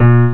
INTERACTIVE PIANO
the note should sound out.